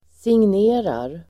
Uttal: [singn'e:rar (el. sinj'e:-)]